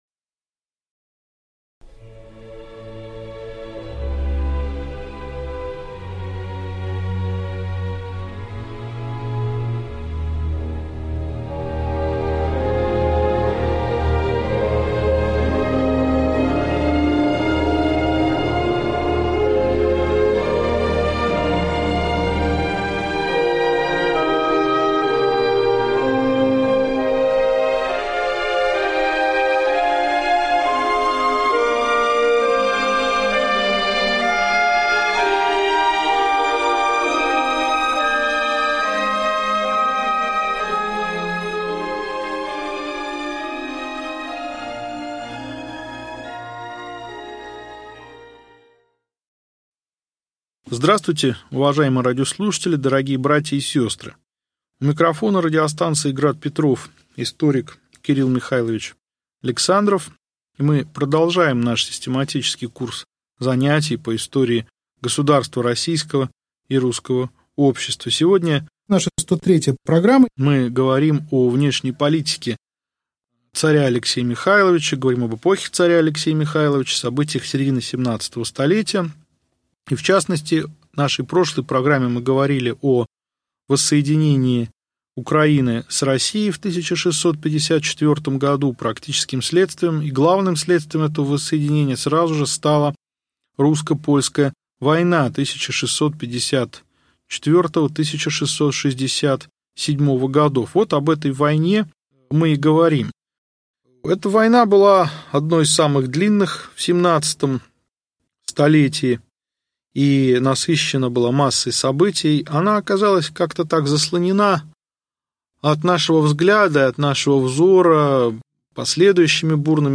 Аудиокнига Лекция 103. Русско-польская война середины XVII в. | Библиотека аудиокниг